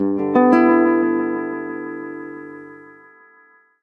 描述：DuB HiM丛林onedrop rasta Rasta雷鬼雷鬼根源根
Tag: onedrop 丛林 配音 雷鬼 拉斯特 拉斯塔 雷鬼